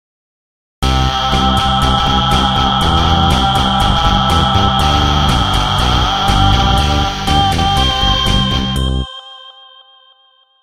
ringtone4